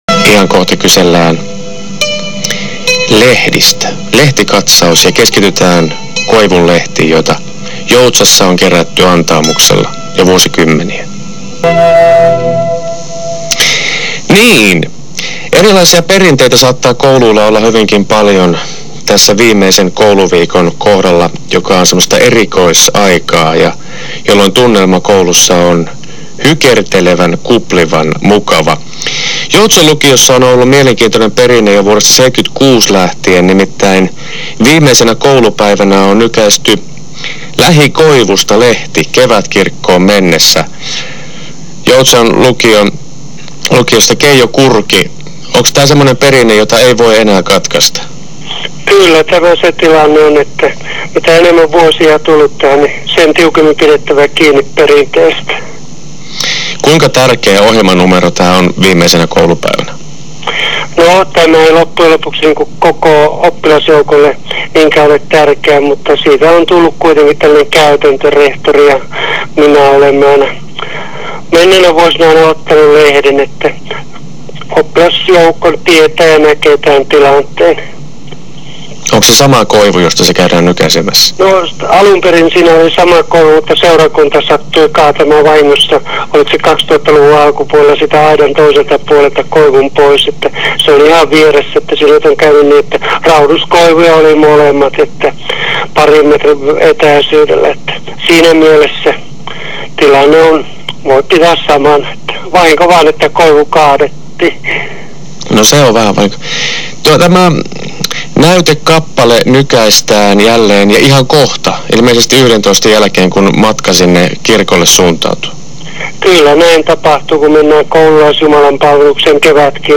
koivunlehtihaastattelu.wma